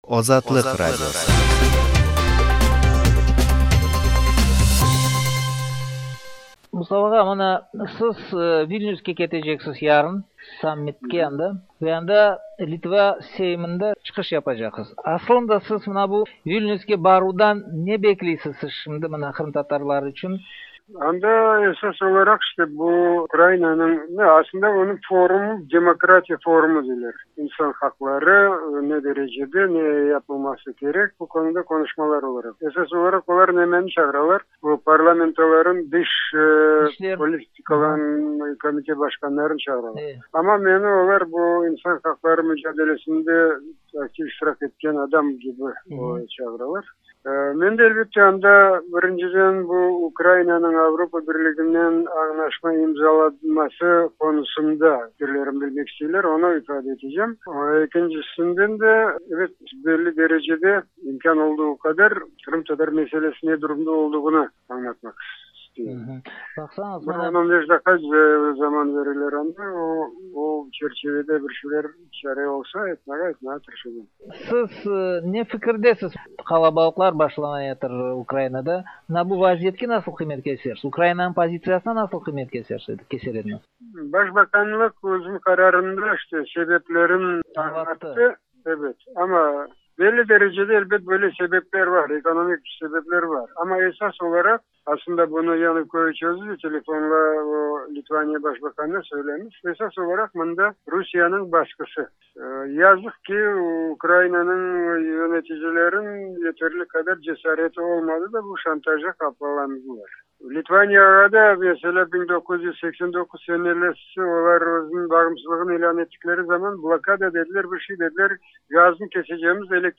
Мостафа Җәмилев Вильнюс алдыннан "Азатлыкка" сөйләде